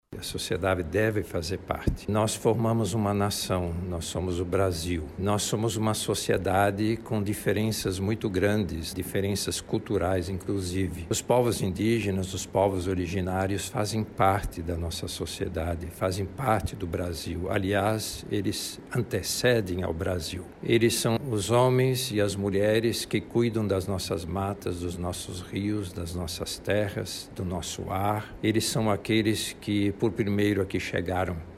A declaração foi feita durante um pronunciamento em que o religioso falou sobre o papel dos povos originários na formação do Brasil.